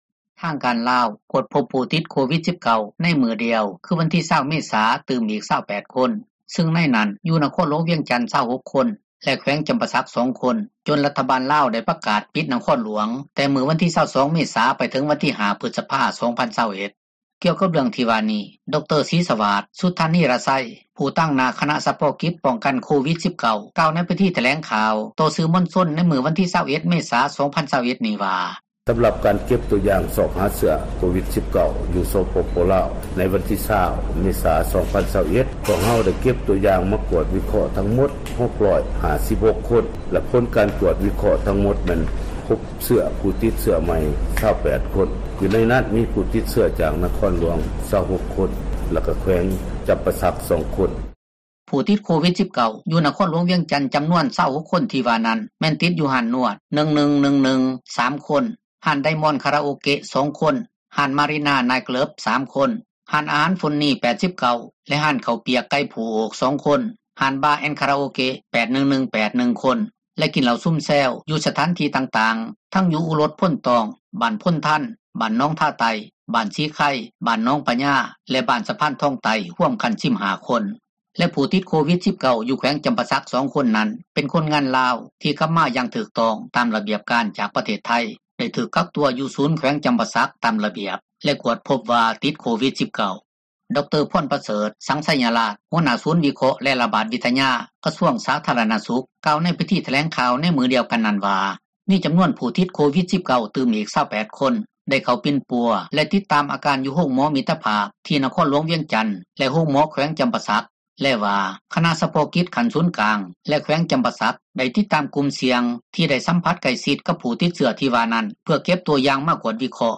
ກ່ຽວກັບເຣື່ອງທີ່ວ່ານີ້ ດຣ. ສີສະຫວາດ ສຸດທານີຣະໄຊ ຕາງໜ້າຄະນະສະເພາະກິດ ປ້ອງກັນໂຄວິດ-19 ກ່າວໃນພິທີຖແລງຂ່າວ ຕໍ່ສື່ມວນຊົນລາວ ໃນມື້ວັນທີ 21 ເມສາ 2021 ນີ້ວ່າ: